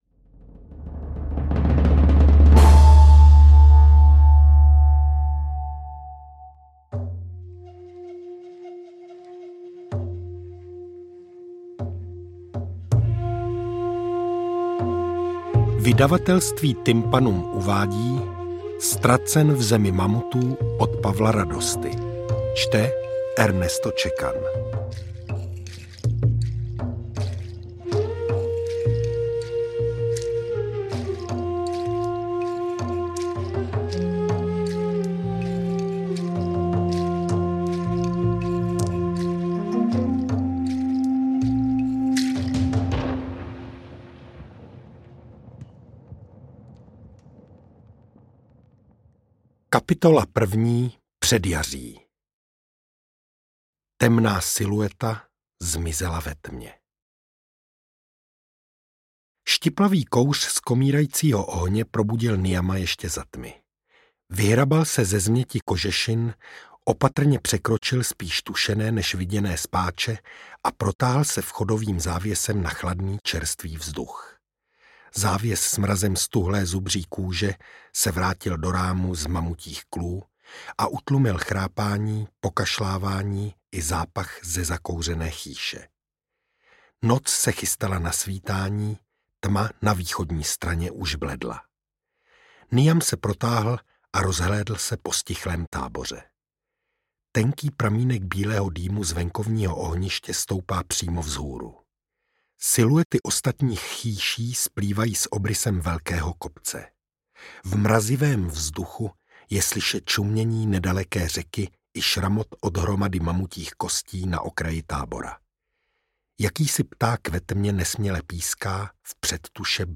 AudioKniha ke stažení, 17 x mp3, délka 6 hod. 32 min., velikost 359,3 MB, česky